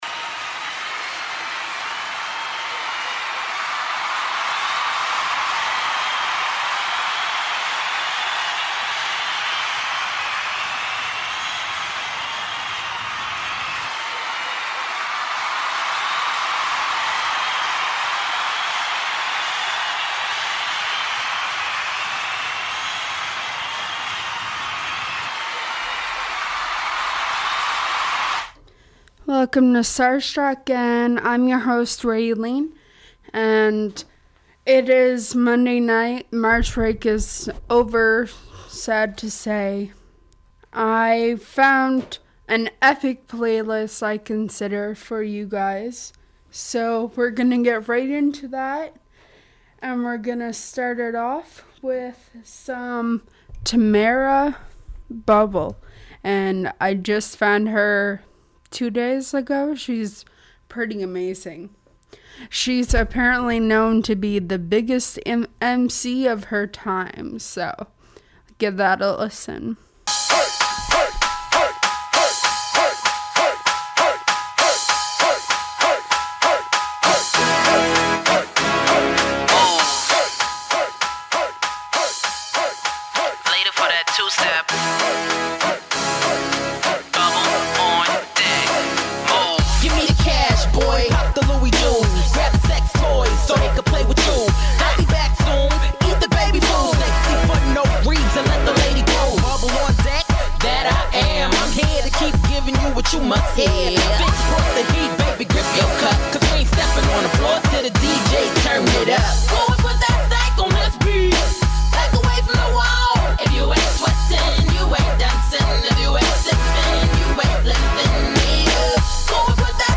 An Open Format Music Show - Pop, Acoustic,Alternative Rock,as well as Local/Canadian artists